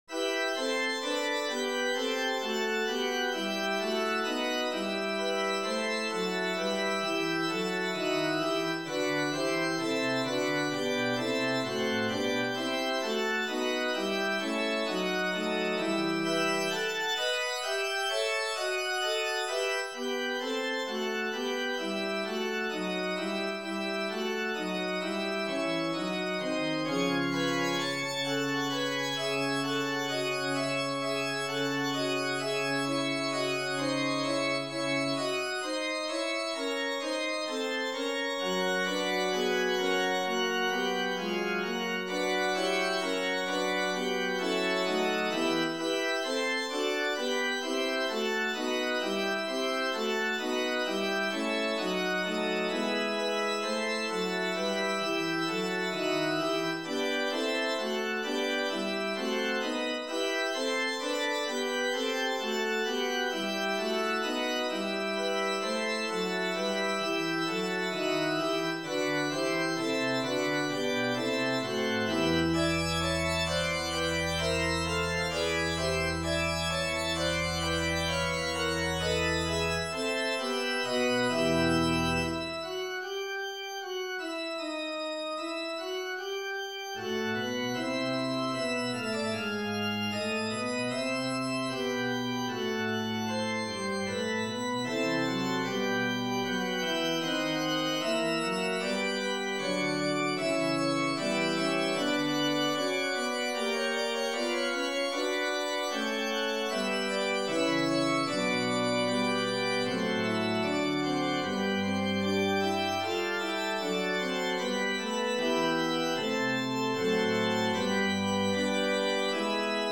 for keyboard